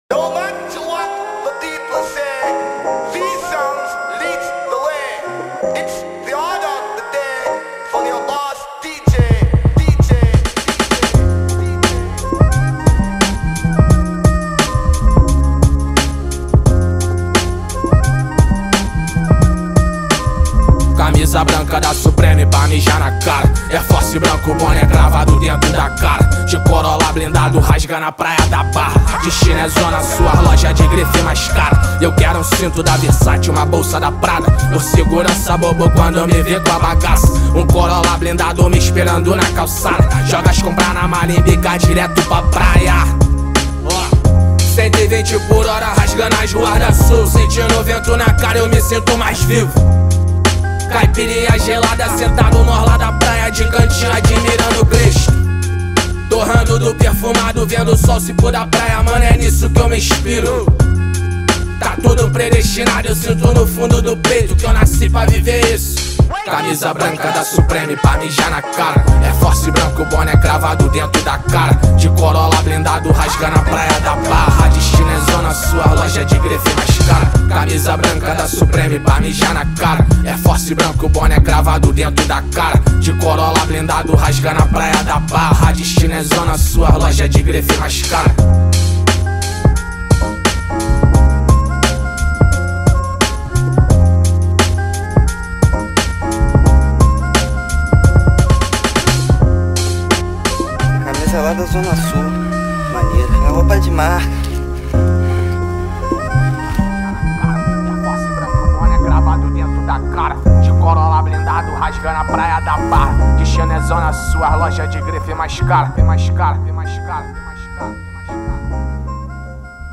2024-06-08 23:51:11 Gênero: Rap Views